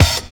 108 KIK+OP-L.wav